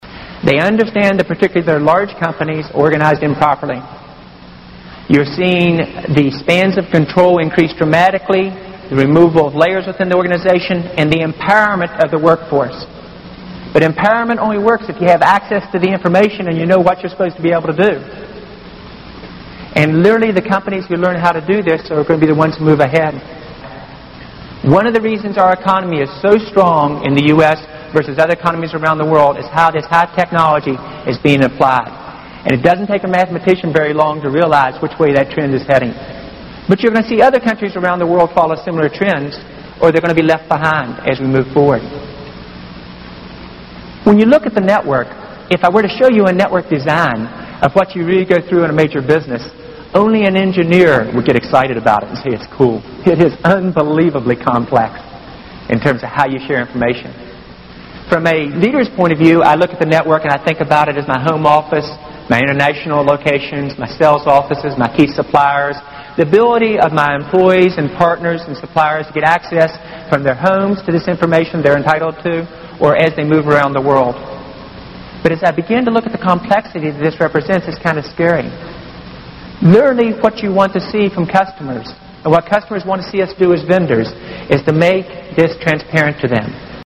财富精英励志演讲 第126期:互联网会改变一切(5) 听力文件下载—在线英语听力室